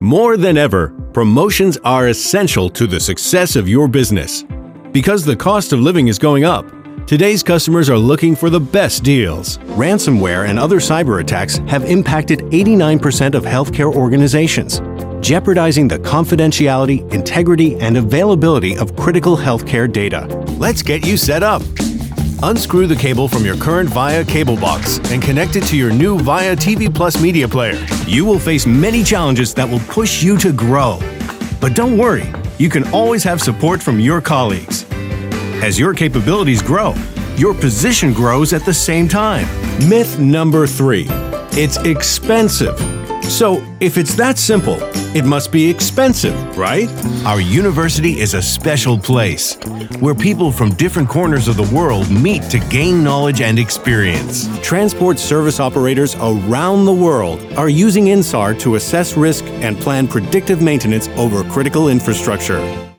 Voice Over Demos: Commercial, Promos, Political, Documentary, eLearning, Animation, Video Games, Explainer Video, and Audio Tour voiceovers.